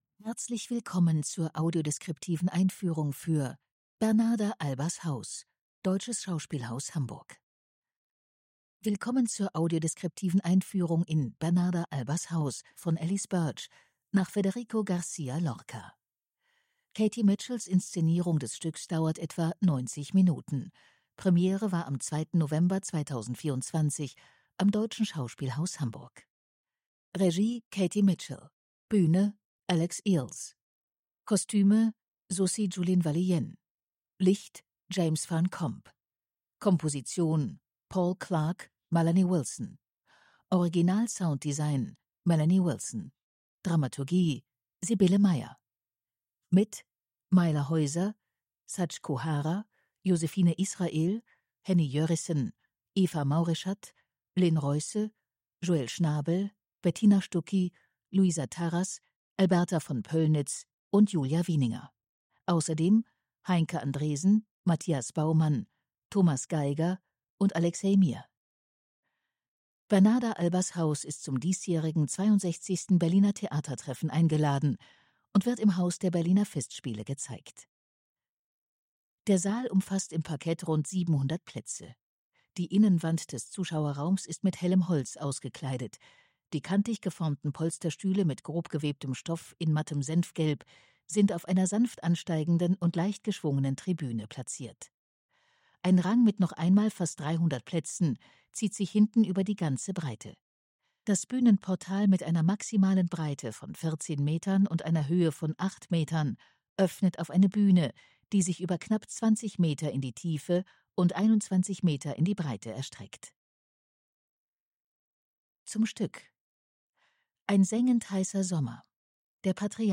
Audiodeskription „Bernarda Albas Haus”
tt25_audiodeskription_bernarda_albas_haus.mp3